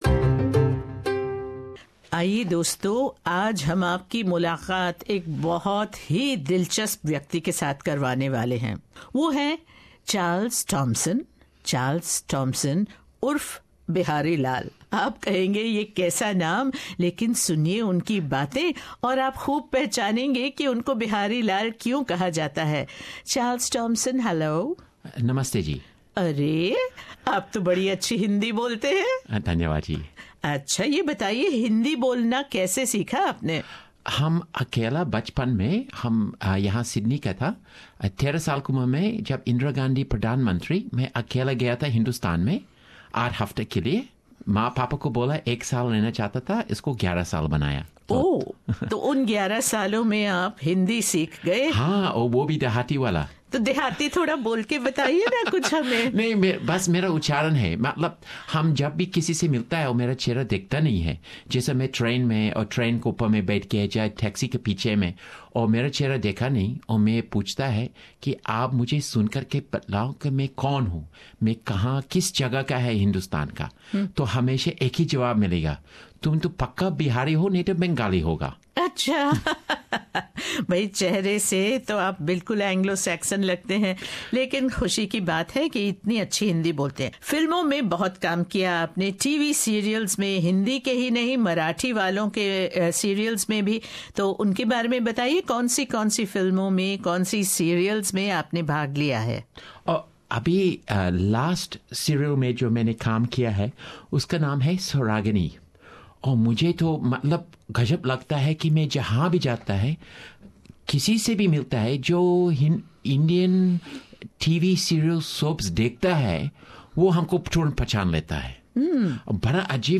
ये एक ऑस्ट्रेलियाई हैं जो फर्राटे से हिंदी बोलते हैं। और तो और हिंदी और मराठी फिल्मों में काम कर रहे हैं।